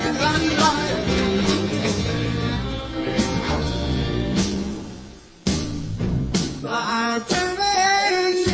blues_blues.00003.mp3